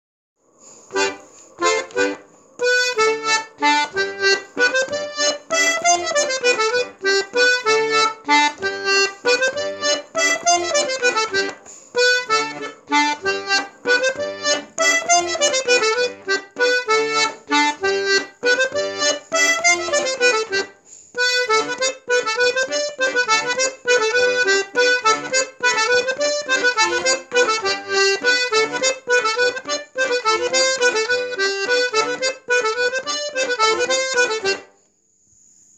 (traditionnel)
Bourrées 3 temps
Bourree Giatte de Coualbon.mp3